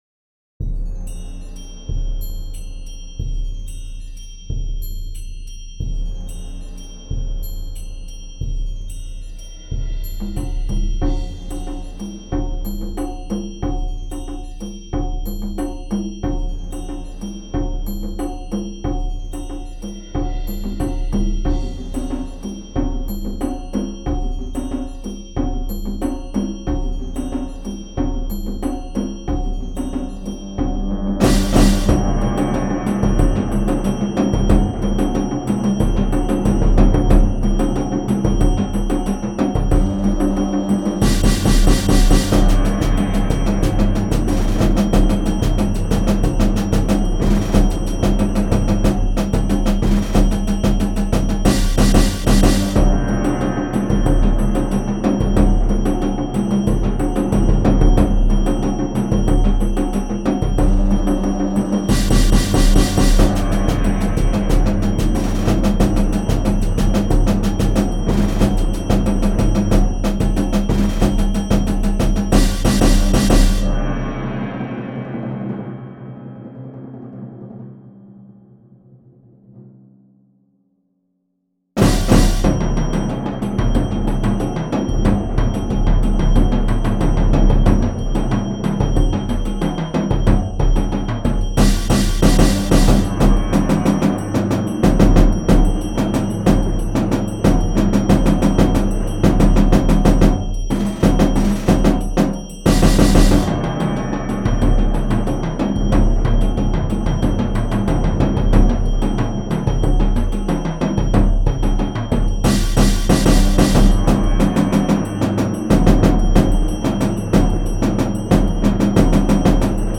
volledig in oosterse stijl
Genre All over the World